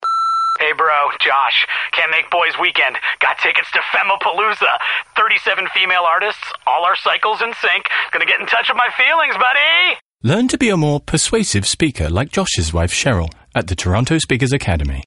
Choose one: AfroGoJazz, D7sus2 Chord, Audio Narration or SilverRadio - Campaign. SilverRadio - Campaign